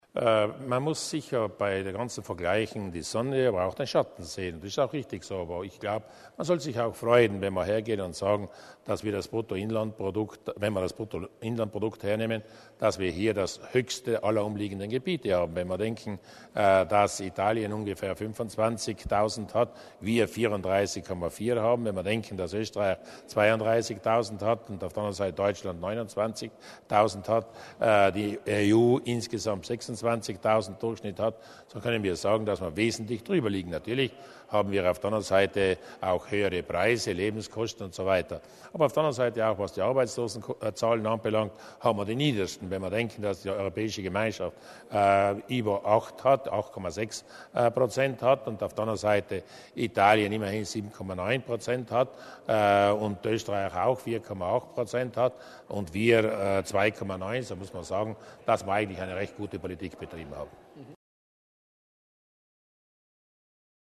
Landeshauptmann Durnwalder zur wirtschaftlichen Lage in Südtirol